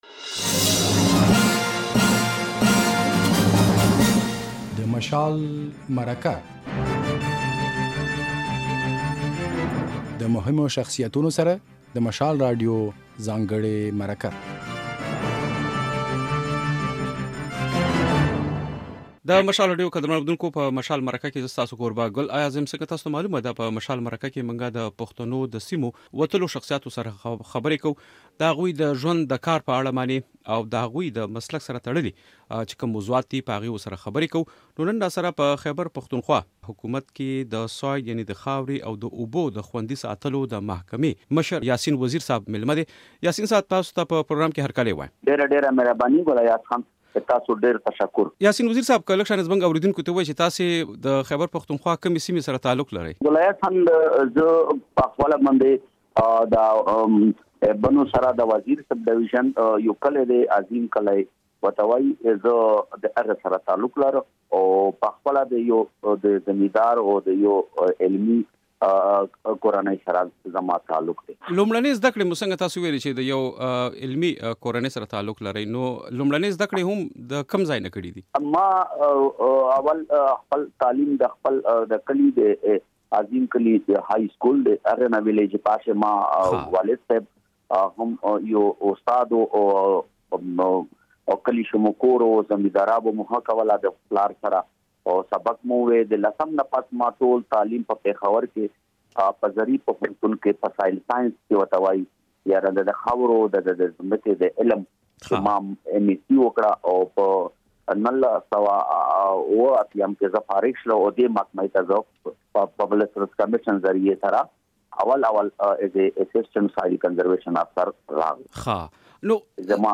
بشپړه مرکه د غږ په ځای کې واورئ.